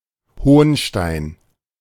[ˈhoːnˌʃtaɪ̯n], česky Honštejn) je město i stejnojmenný hrad v německé spolkové zemi Sasko.
De-Hohnstein.ogg